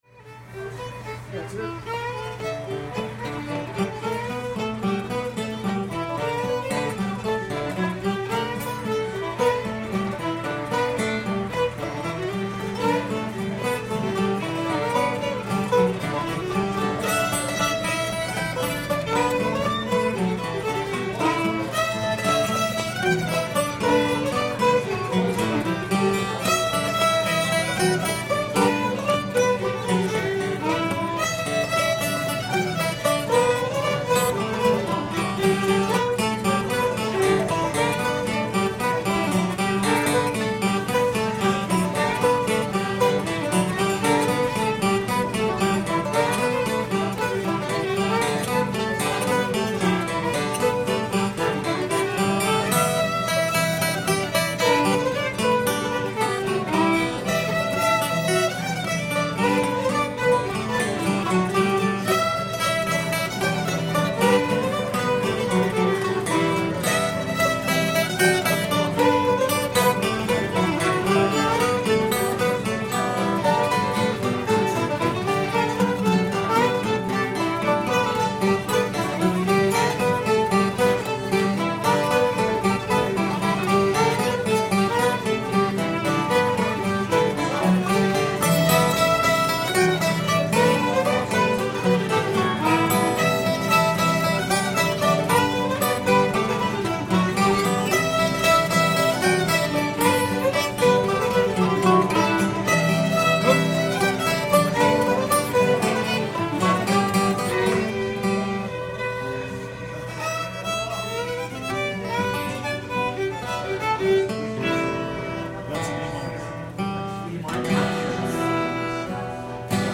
old aunt jenny with her nightcap on [G]